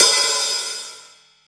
paiste hi hat9 open.wav